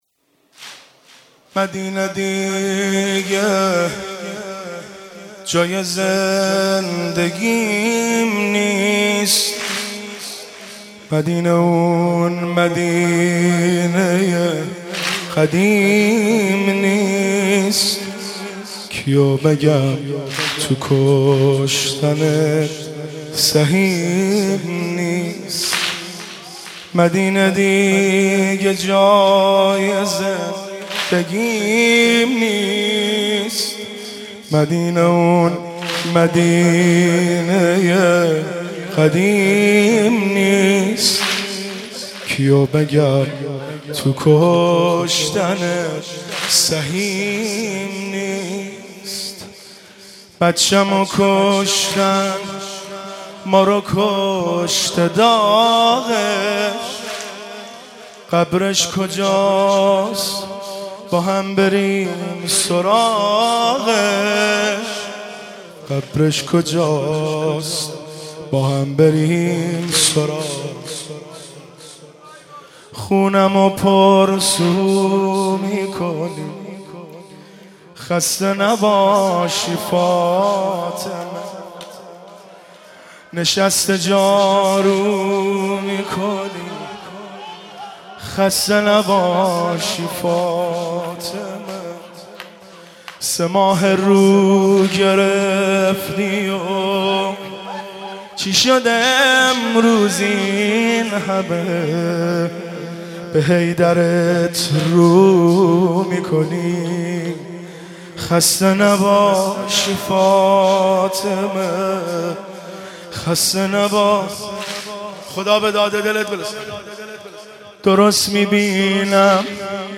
مناسبت : شهادت حضرت فاطمه زهرا سلام‌الله‌علیها1